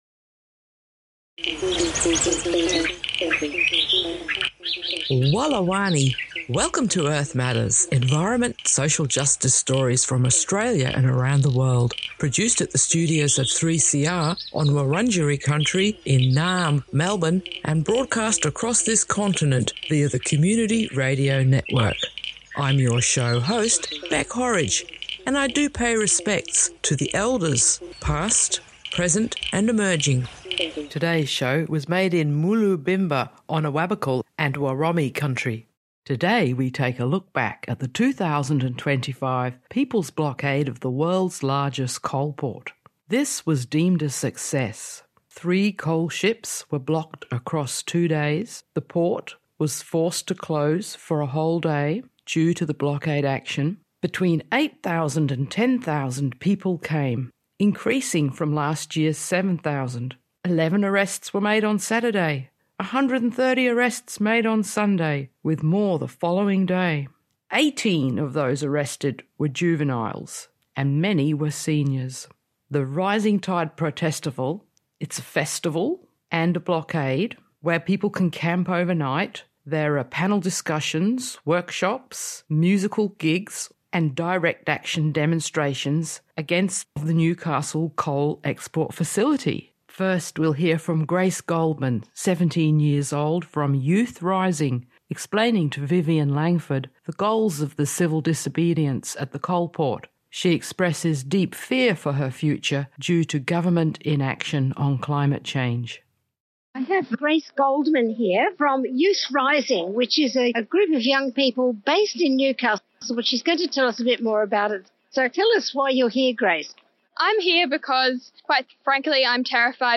Hear the rapturous cheers when Rising Tide coal port blockade succeeds in stopping coal ships.
Earth Matters brings you Voices from "Rising Tide" protest blocking coal ships in Moolobinba/Newcastle, highlighting youth climate anxiety, calls for government accountability, and the interconnectedness of environmental, social, and Indigenous justice issues in Australia.